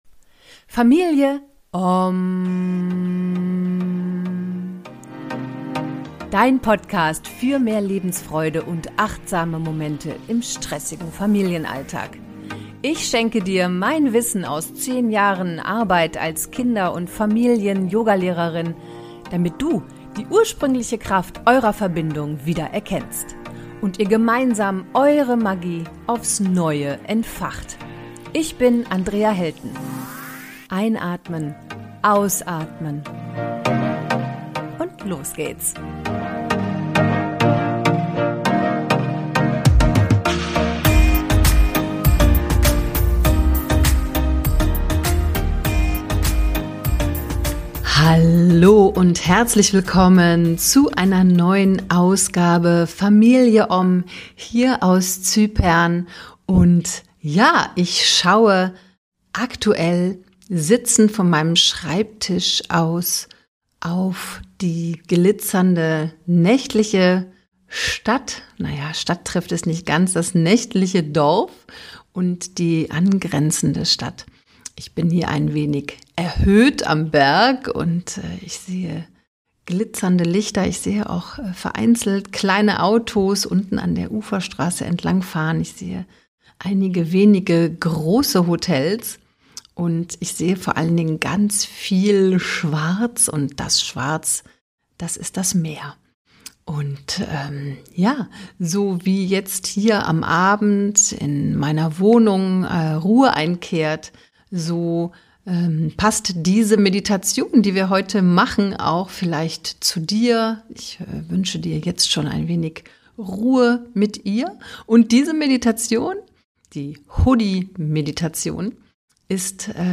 #126 Dein Hoodie. Dein Raum. | Meditation für Teenies (10 Min. ohne Musik) ~ Familie Ommm Podcast